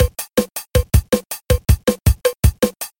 ・ROM 1 LM-1 に近い